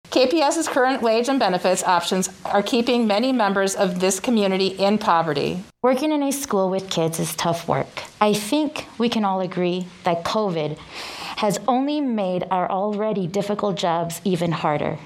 KALAMAZOO, MI (WKZO AM/FM) – Support staff for Kalamazoo Public Schools turned out in large numbers at the district’s Board of Education meeting Thursday night, demanding salary hikes and getting a positive response from the board.